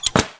WelderActivate.ogg